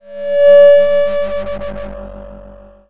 distort_feedback_02.wav